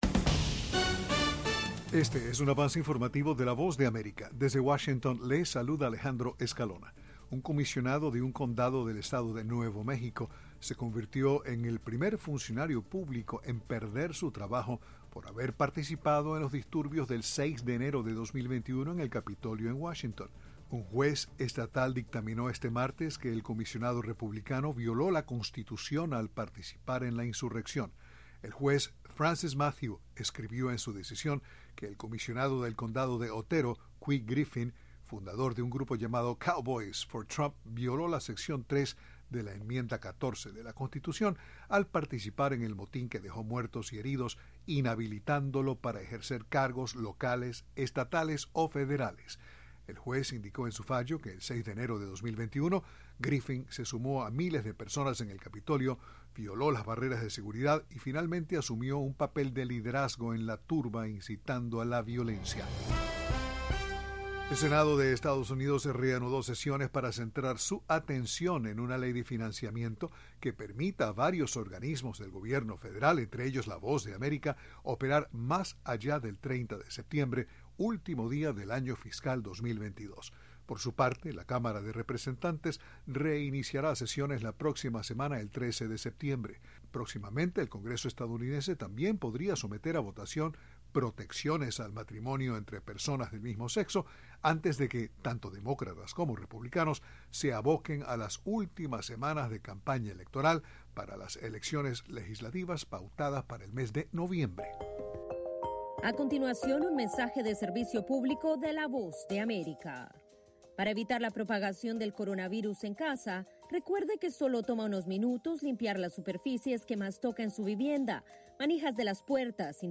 Avance Informativo 6:00pm
Este es un avance informativo presentado por la Voz de América en Washington.